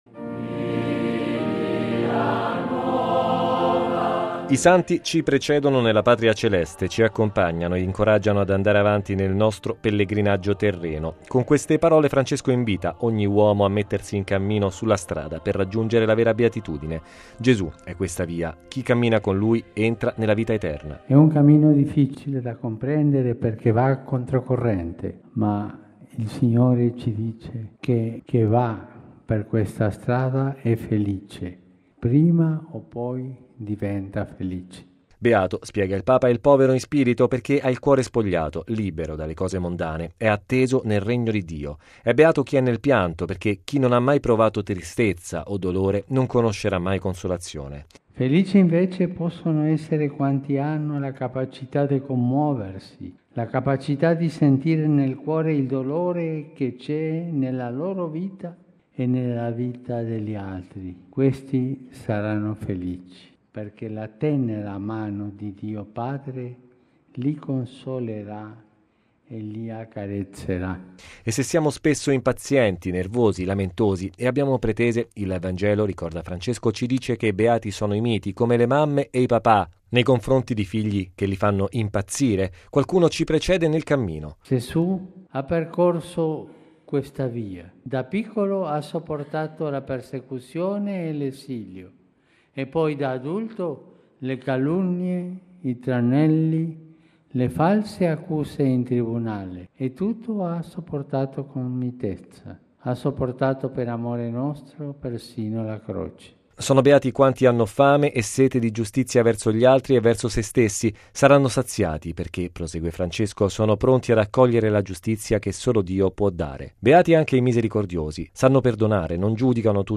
Così il Papa ieri pomeriggio celebrando la Messa nella Solennità di Tutti i Santi nel Cimitero del Verano a Roma.